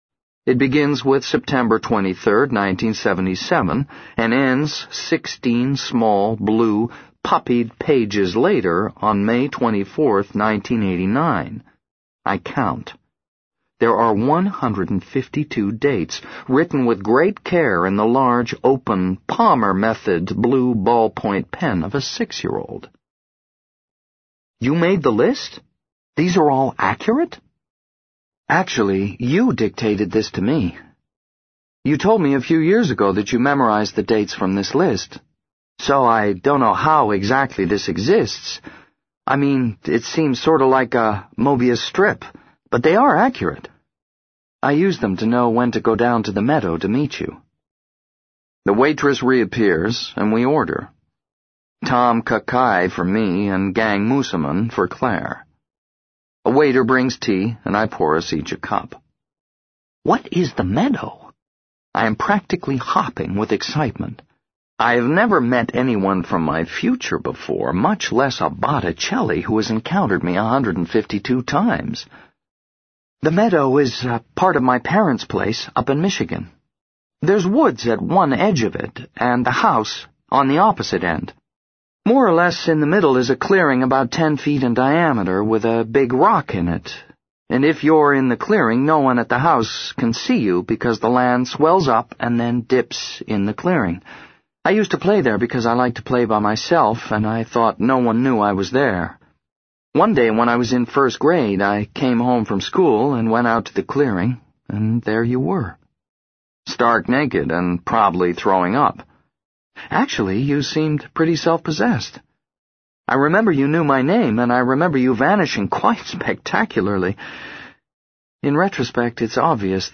在线英语听力室【时间旅行者的妻子】10的听力文件下载,时间旅行者的妻子—双语有声读物—英语听力—听力教程—在线英语听力室